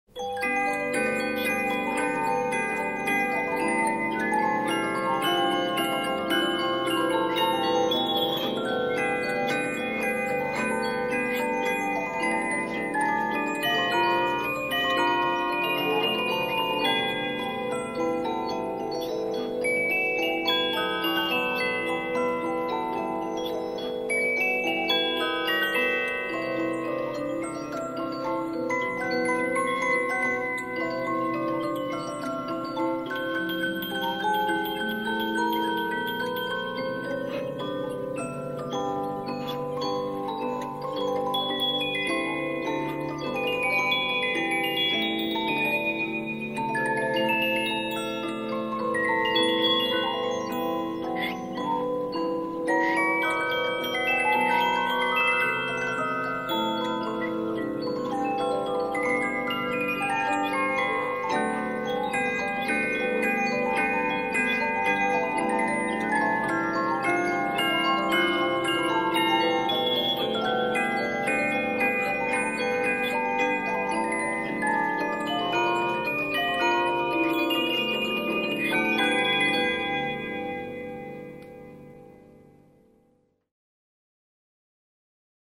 Music Box - Swiss - The Metropolitan Museum of Art